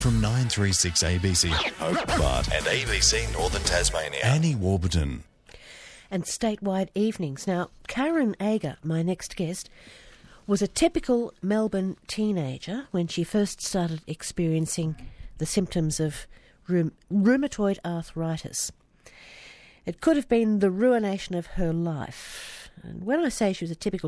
with Margaret Throsby on ABC